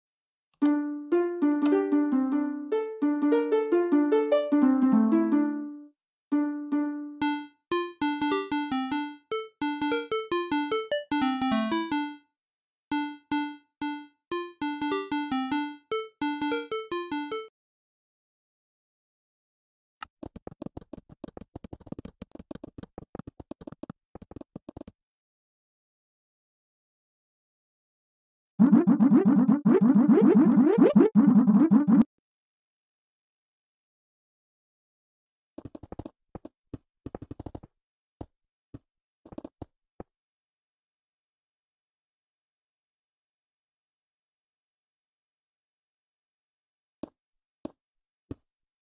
Minecraft Noteblocks